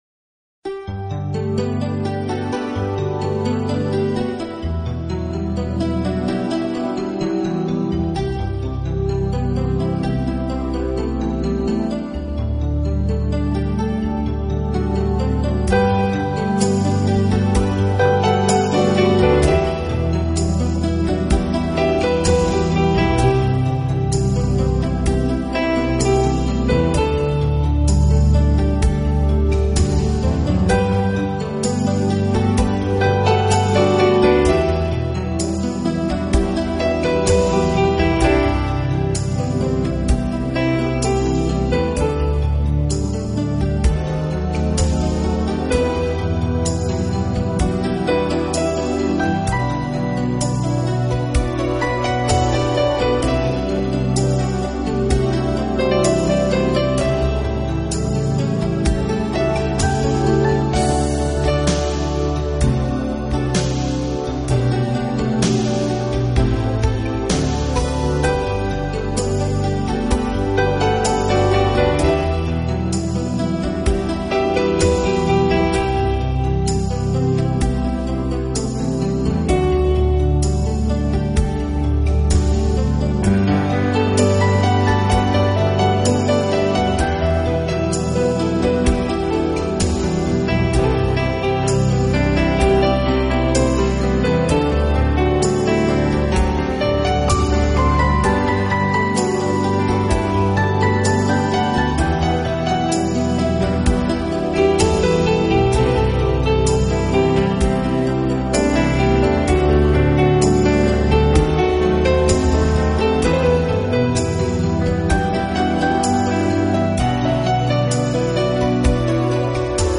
音乐类型：Newage/Classical